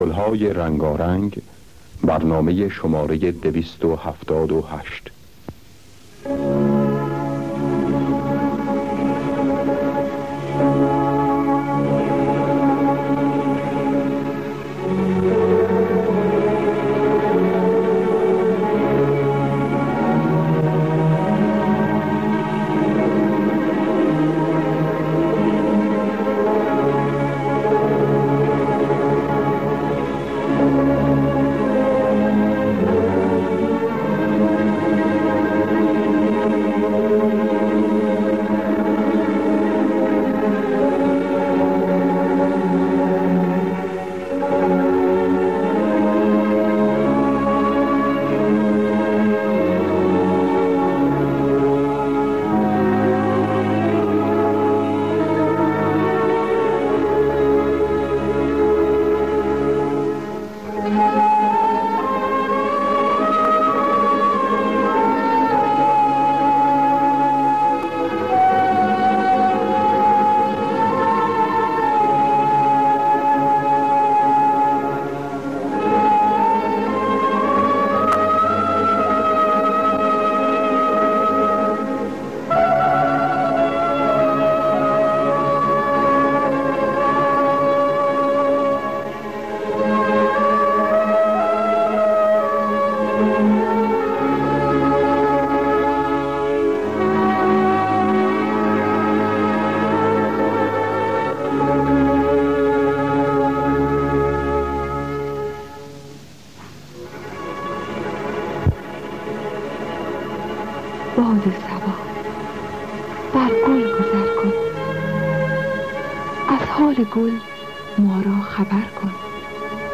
دانلود گلهای رنگارنگ ۲۷۸ با صدای پروین، محمودی خوانساری در دستگاه همایون.
خوانندگان: پروین محمودی خوانساری نوازندگان: مرتضی محجوبی جواد معروفی